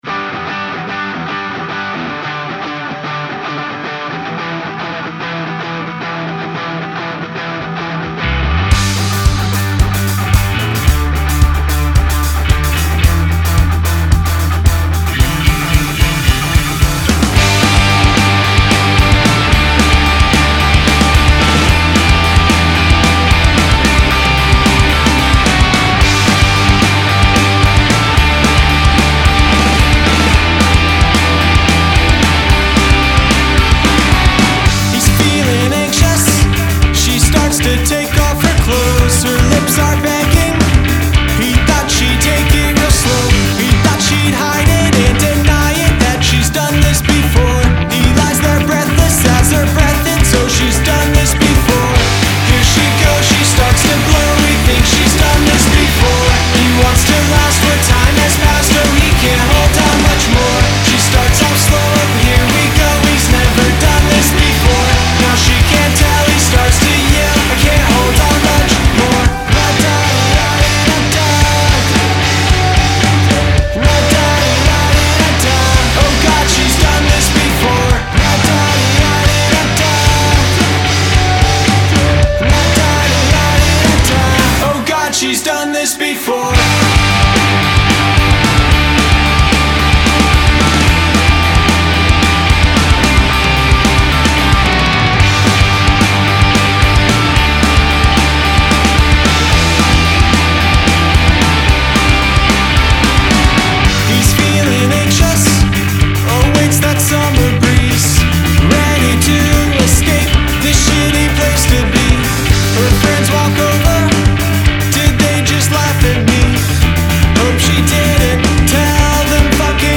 iconic early 2000's summer pop-punk feel